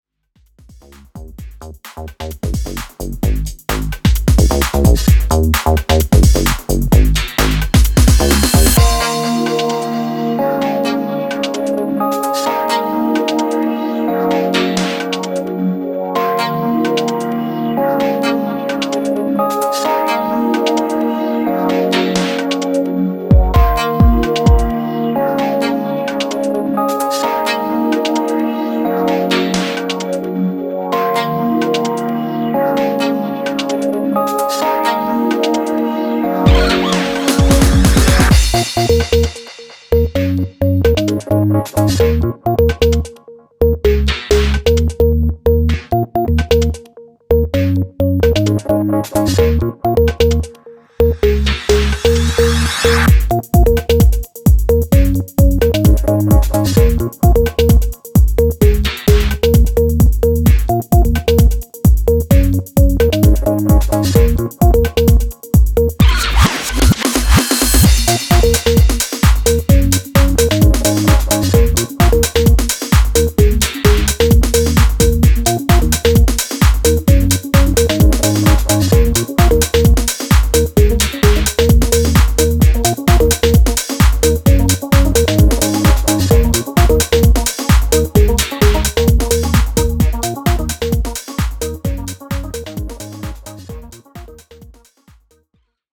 Genre Electro , House , Tech House